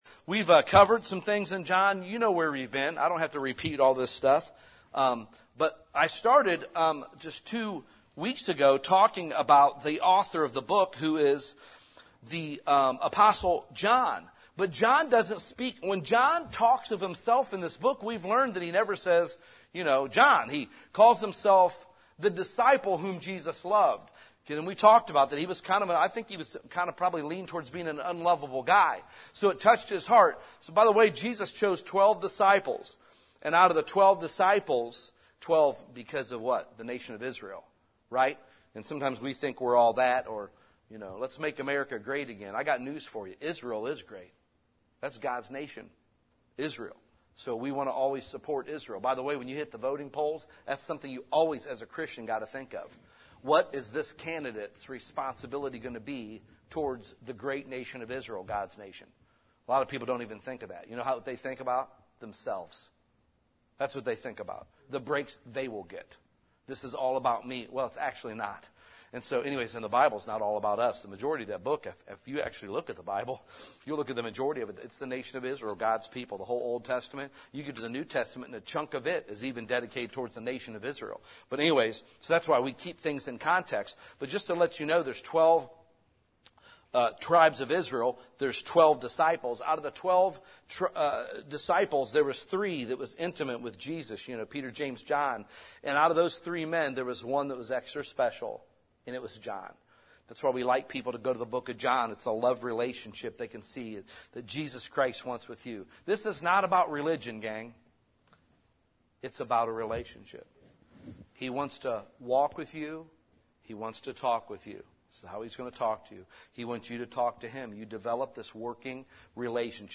Sermons | Maple City Baptist Church